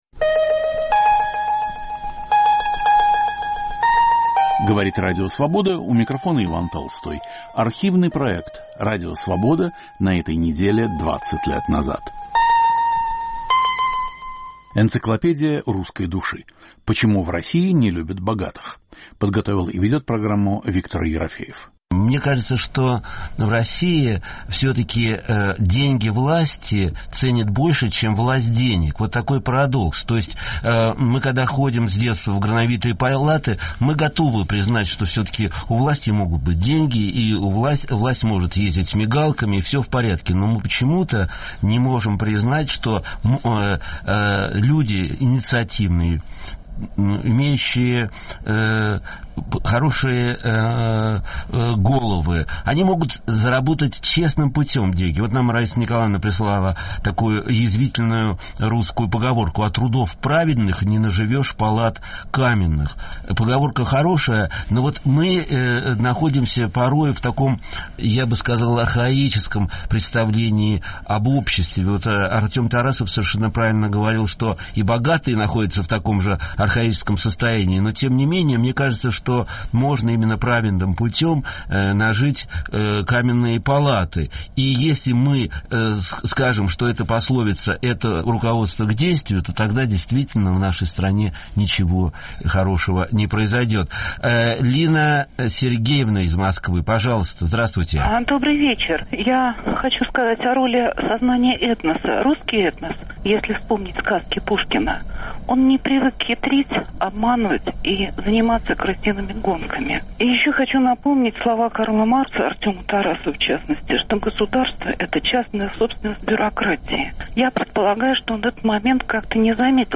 Гости - поэт Евгений Рейн и бизнесмен Артем Тарасов. Автор и ведущий Виктор Ерофеев.